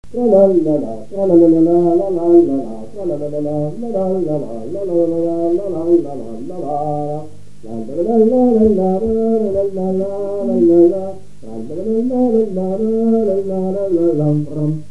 gestuel : danse
Pièce musicale inédite